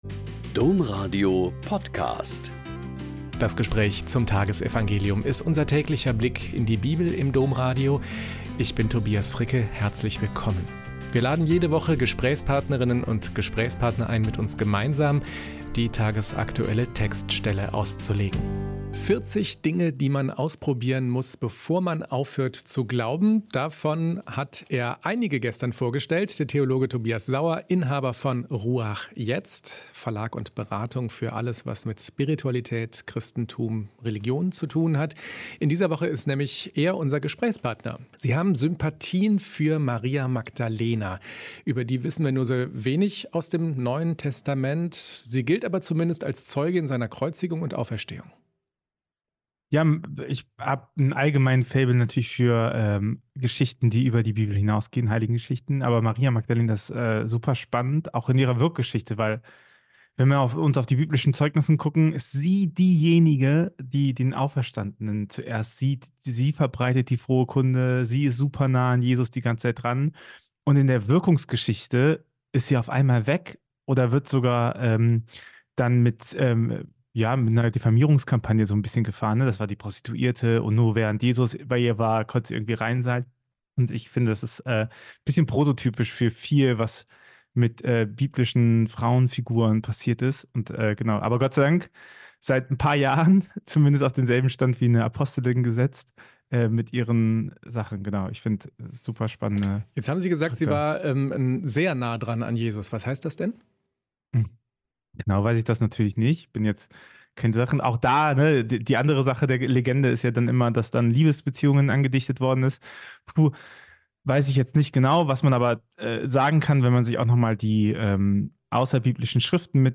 Mt 11,28-30 - Gespräch